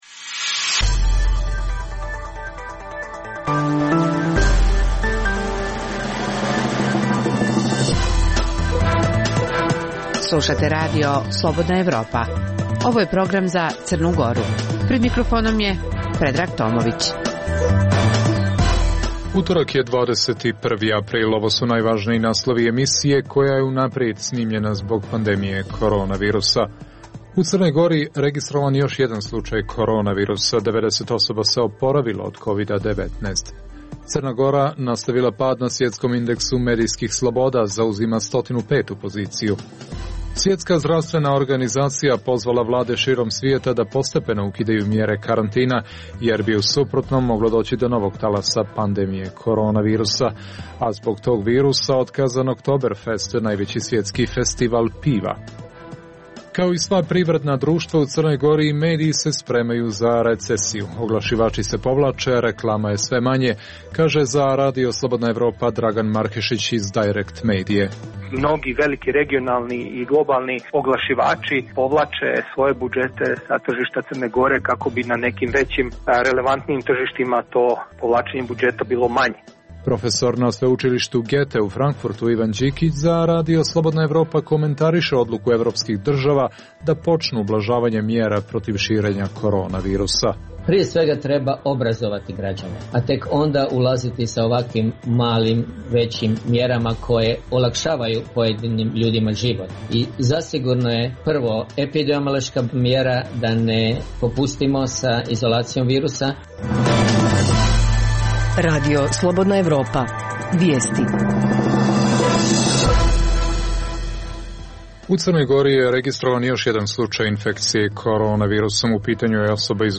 Emisija je unaprijed snimljena zbog pandemije korona virusa.